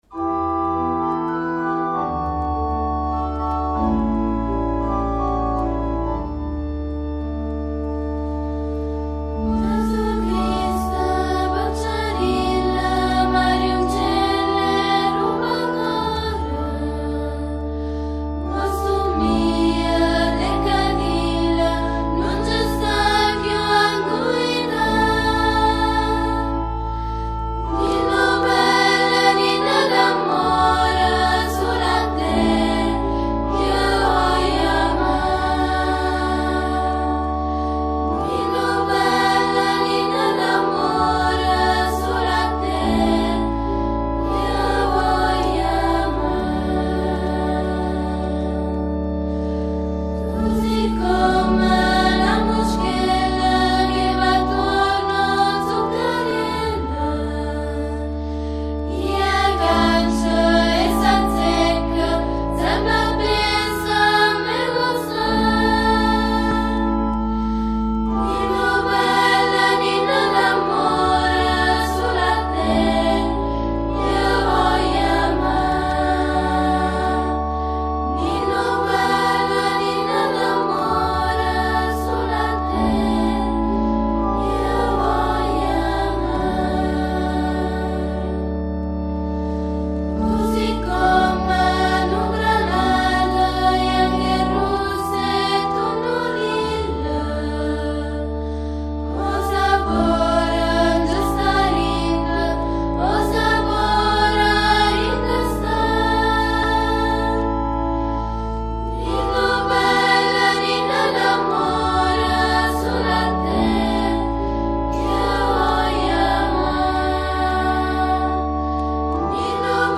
Una canzoncina di S. Alfonso
Ascolta e scarica Giesù Criste peccerille dal Piccolo Coro dell’Annunziata di S. Agata dei Goti *mp3